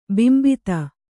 ♪ bimbita